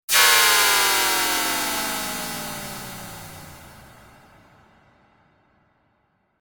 Sci-fi-space-deceleration-sound-effect.mp3